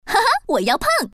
Index of /hunan_master/update/12813/res/sfx/common_woman/